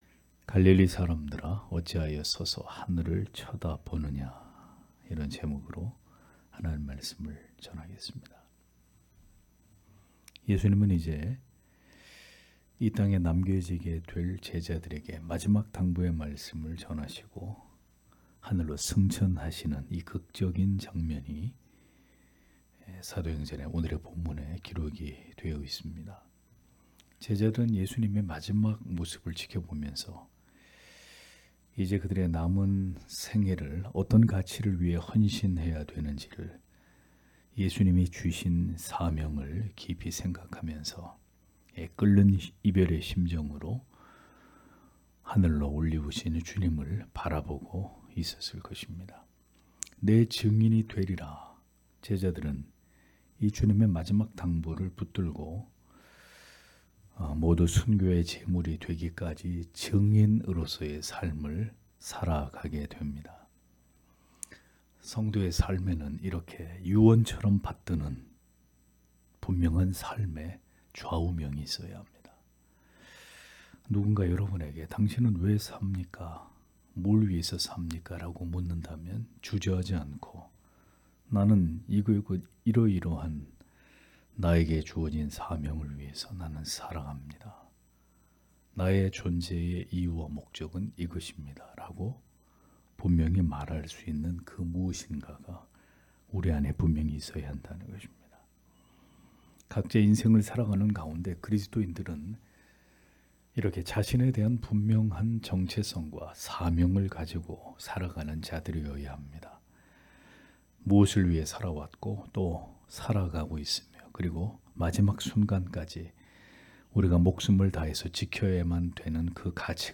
금요기도회 - [사도행전 강해 04] 갈릴리 사람들아 어찌하여 서서 하늘을 쳐다보느냐 (행 1장 9- 11절)